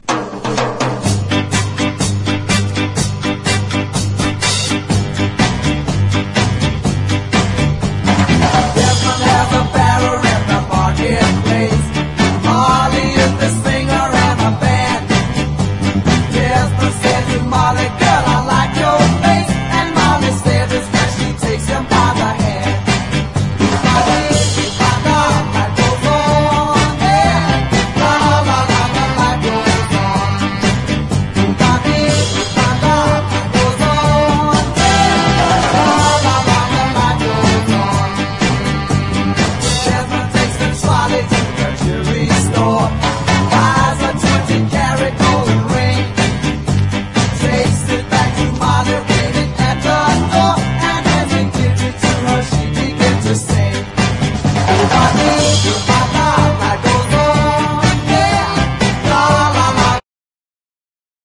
SOUL / SOUL / 60'S / FUNK / RARE GROOVE
燃えるファンキー・ソウル！